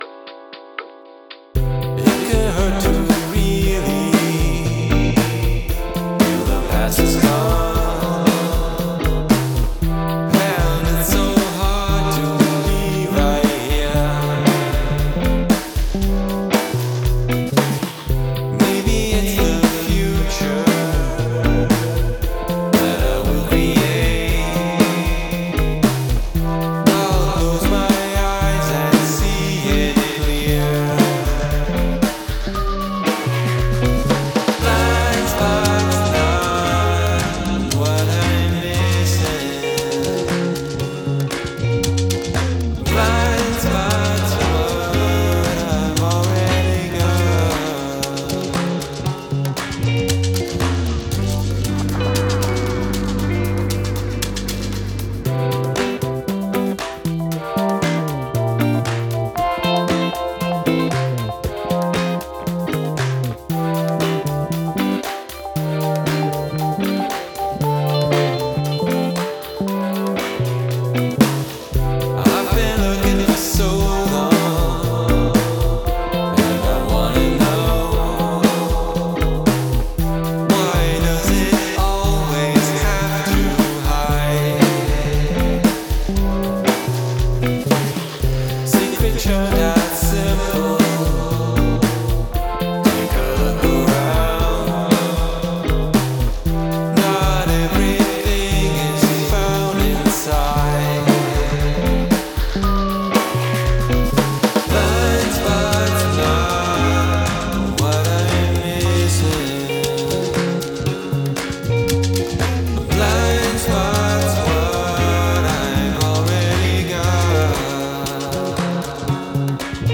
Hand percussion
Loungey? I like the shimmery guitar/keys(?)...
I dig the stereo-ness. Cool guitar solo.